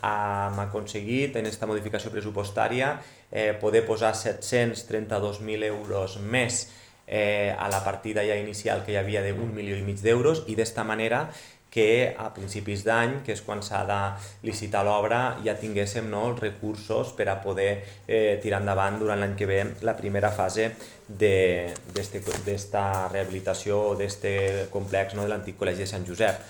Jordi Jordan, Alcalde de Tortosa…